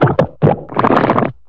schlamm.wav